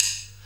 D2 SDRIM07-R.wav